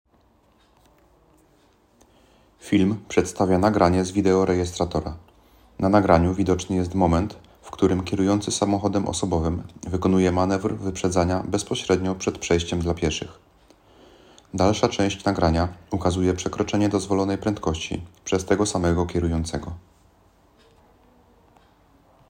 Nagranie audio Audiodeskrypcja - Na drodze nie ma miejsca na lekceważenie przepisów – młody kierowca ukarany za niebezpieczne zachowanie